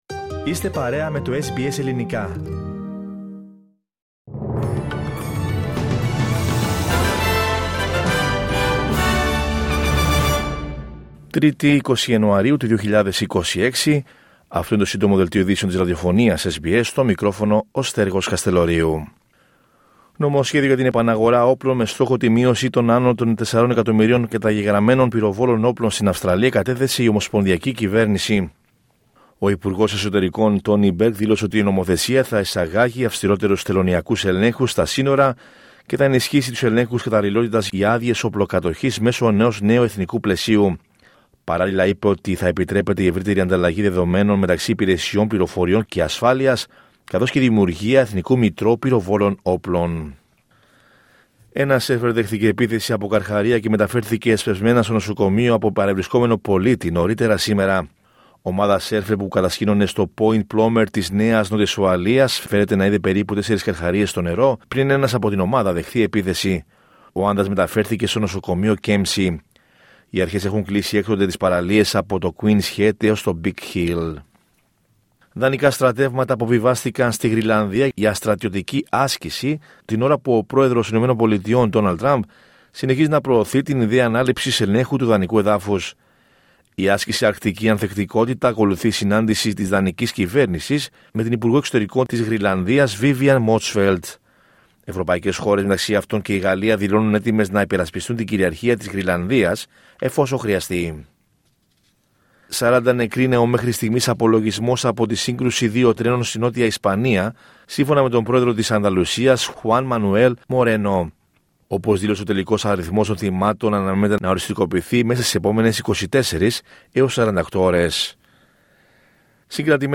H επικαιρότητα έως αυτή την ώρα στην Αυστραλία, την Ελλάδα, την Κύπρο και τον κόσμο στο Σύντομο Δελτίο Ειδήσεων της Τρίτης 20 Ιανουαρίου 2026.